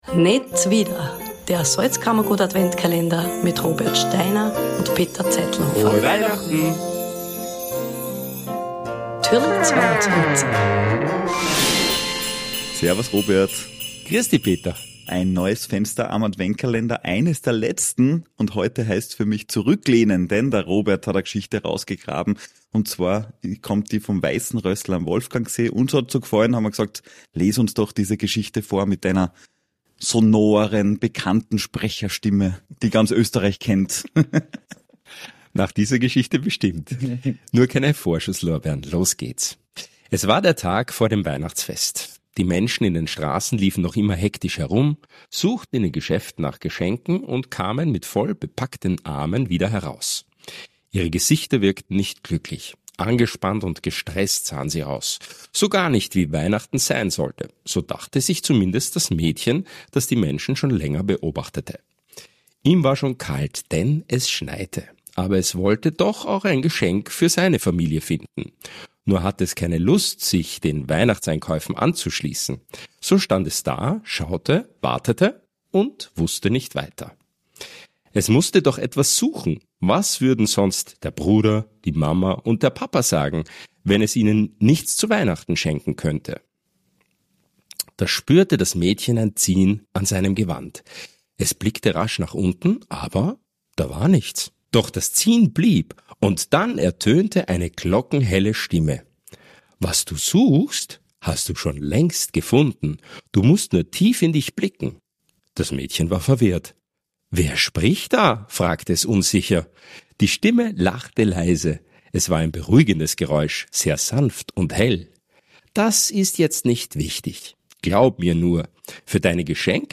Heute lesen wir euch die Geschichte "Das weihnachtliche Leuchten am